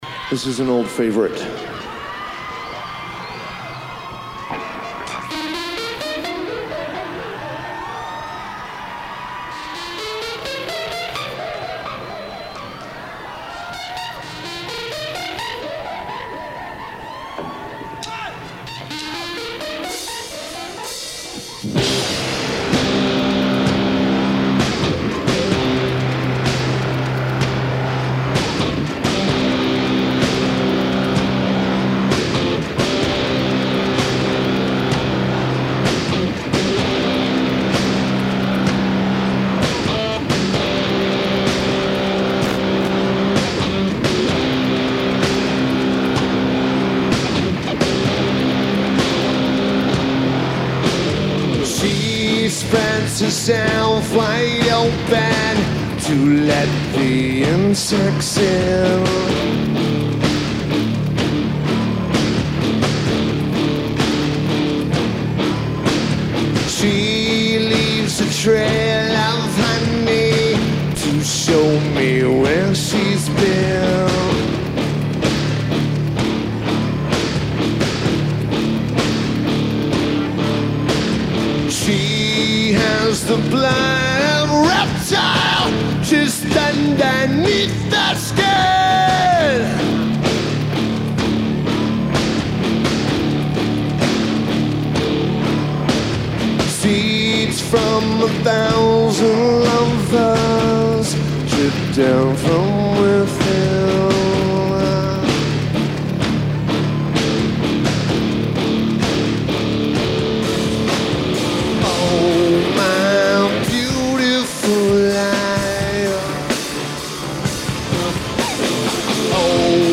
San Diego, CA United States
Guitar
Drums
Lineage: Audio - AUD (IEM > MD)